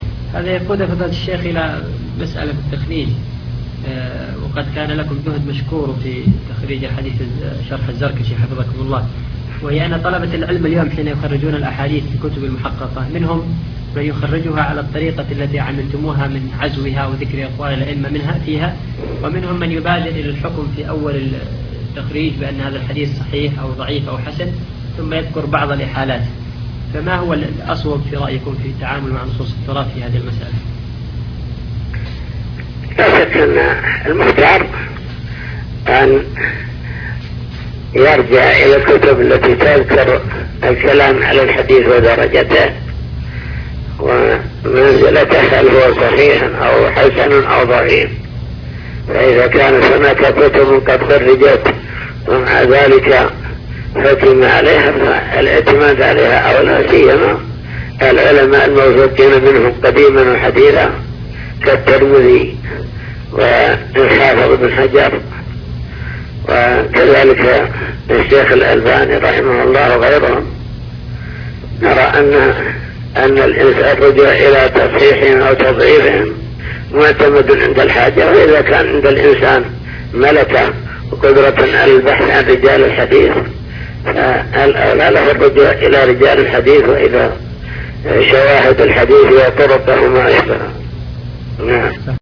حوار هاتفي مع الشيخ